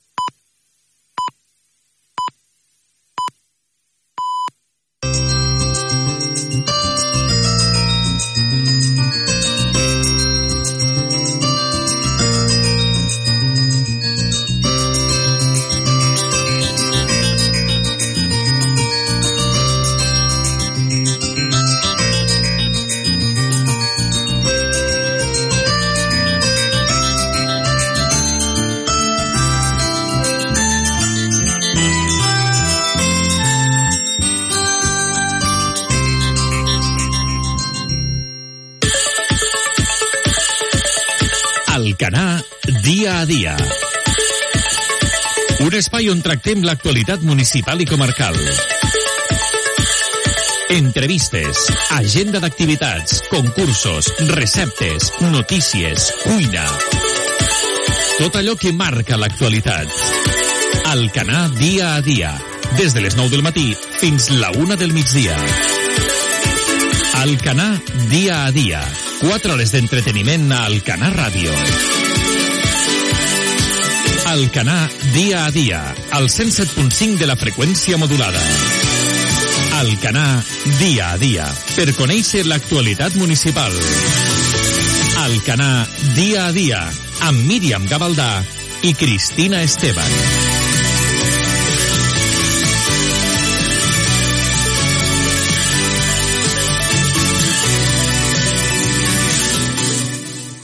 Senyals horaris, sintonia de La Xarxa i careta del programa.
Info-entreteniment